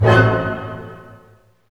HIT ORCHD0FR.wav